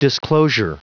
Prononciation du mot disclosure en anglais (fichier audio)
Prononciation du mot : disclosure